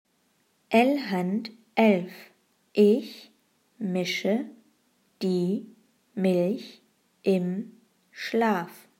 Satz 1 Langsam